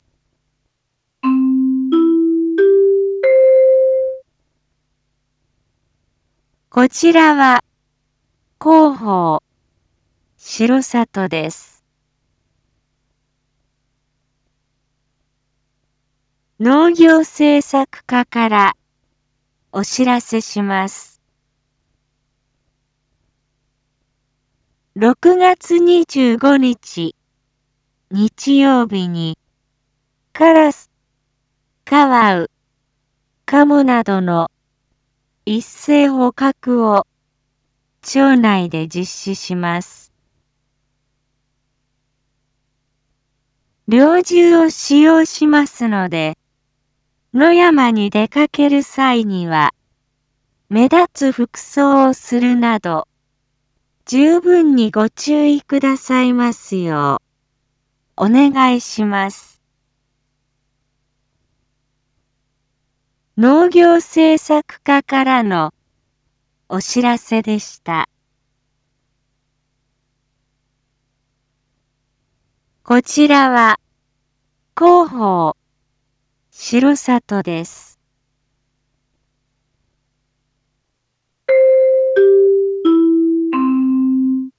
Back Home 一般放送情報 音声放送 再生 一般放送情報 登録日時：2023-06-24 07:01:26 タイトル：R5.6.24（7時）有害鳥獣 インフォメーション：こちらは広報しろさとです。